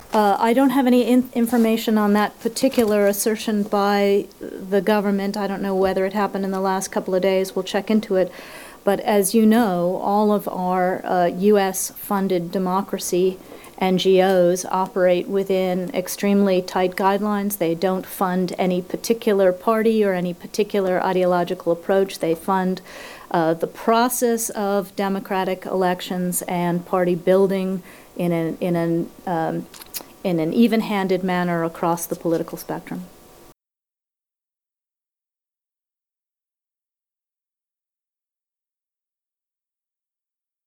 Viktoriya Nuland danışır